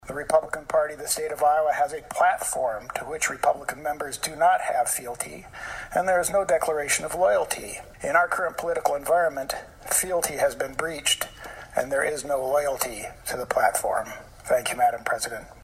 Campbell did not directly mention being stripped of all of his committee assignments as he concluded his two minute speech.